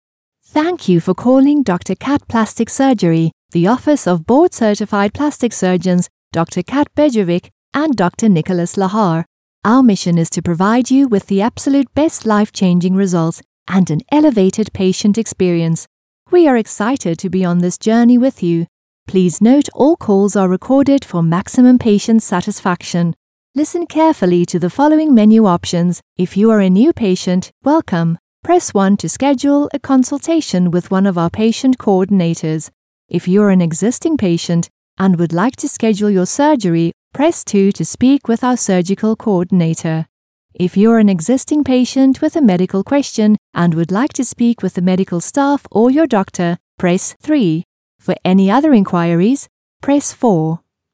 English (South African)
IVR
Behringer C1 Condenser microphone
Sound-proofed room
HighMezzo-Soprano